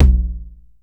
TR 909 Tom 01.wav